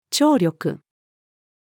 聴力-female.mp3